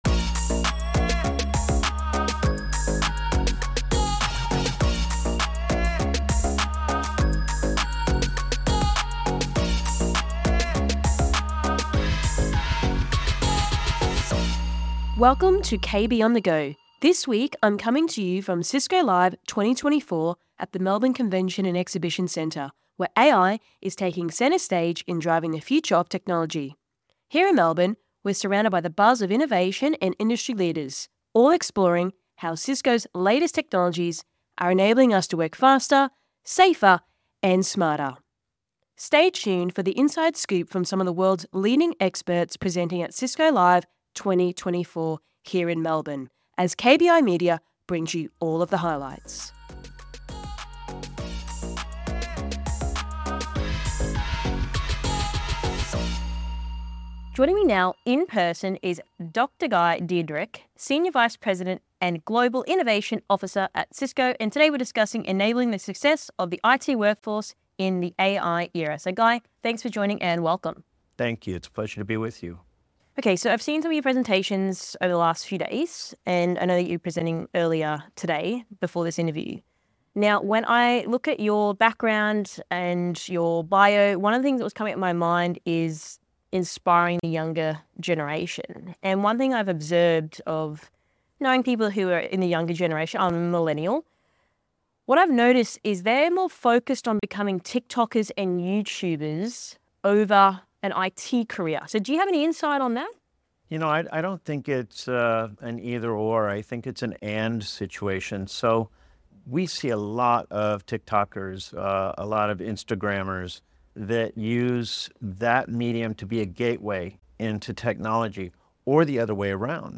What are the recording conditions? From Cisco Live 2024 Melbourne